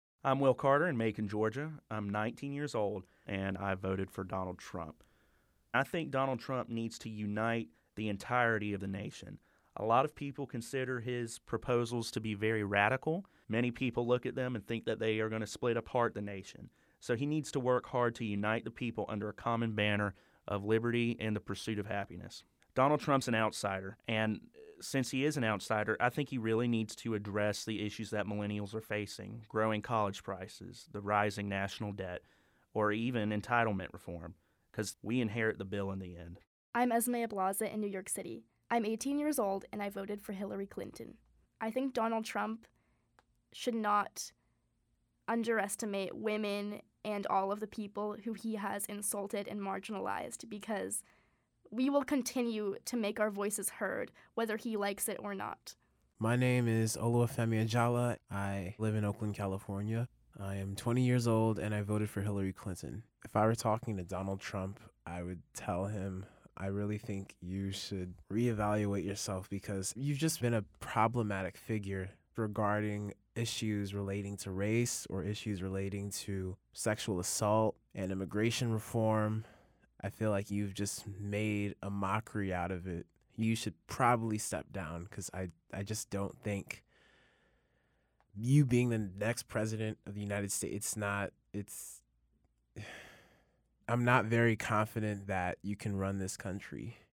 What does Donald Trump’s win mean for young people around the country? Youth Radio asked three voters who cast a ballot for president for the first time.